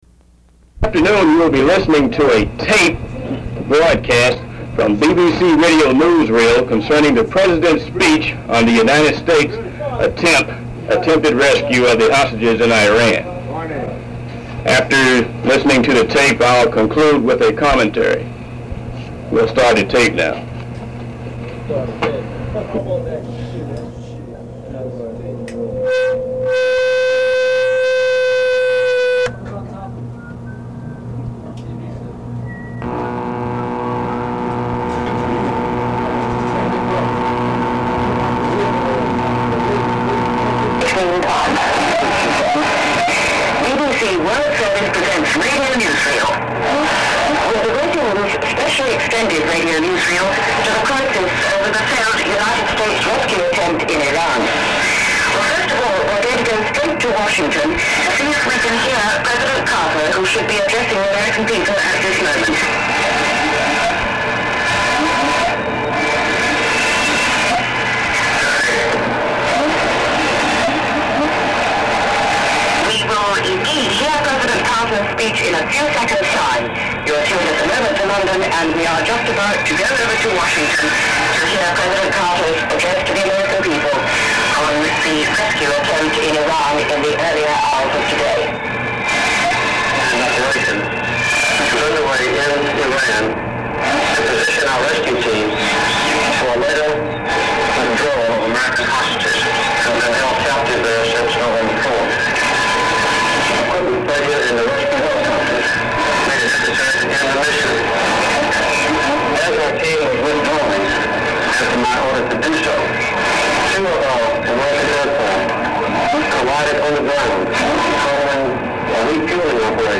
I made this recording by holding up a tape recorder to the closed circuit tv in the berthing area on board the USS Okinawa. It is the actual speech by Jimmy Carter to the United States.
What is interesting, it was played by the BBC, we did not record the Voice of America! This recording is a little scratchy, but it is the authentic recording taken while I was standing under the closed circuit television in the troop berthing area.
original_bbc_recording_rescue_attempt.mp3